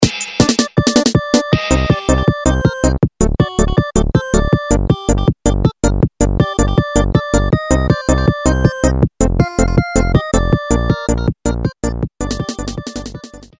Siemens полифония. Шансон